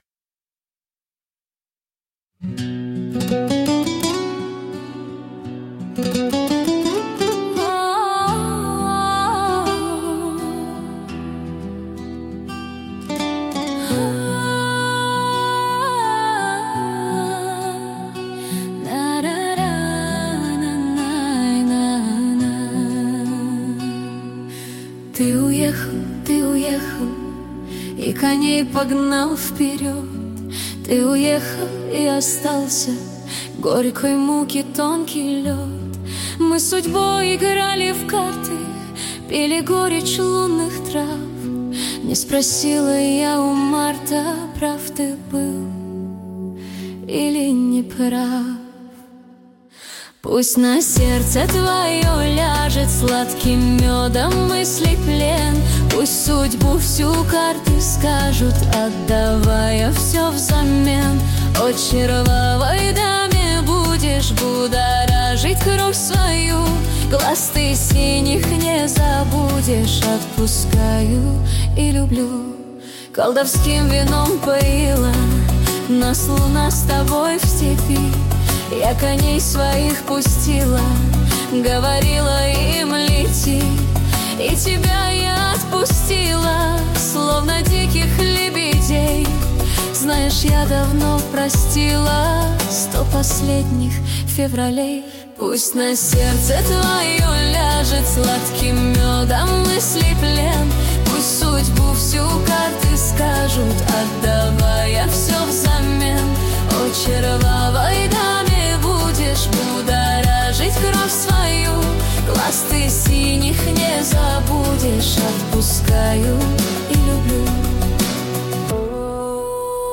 \ Господи\( авторская песня
Сегодня я представляю вашему вниманию песню 🎶 - молитву " Господи"...Пока это отрывок